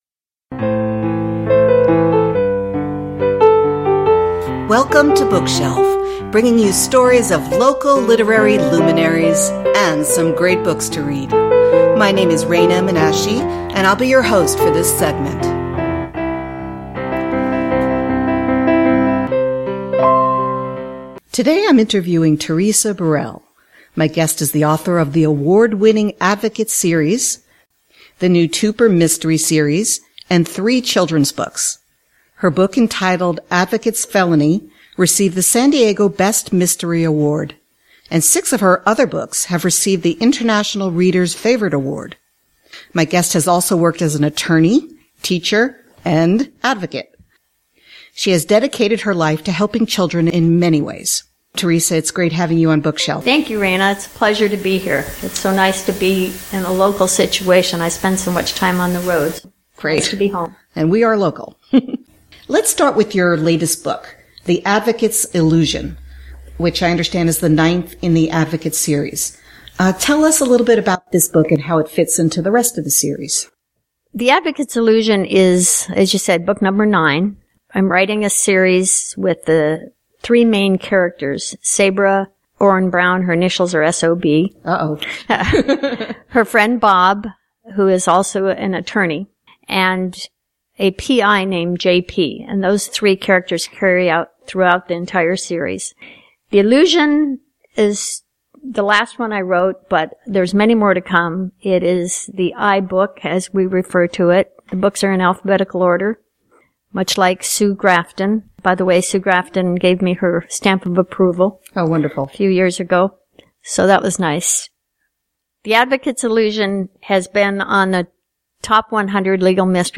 East County Magazine Live! Radio Show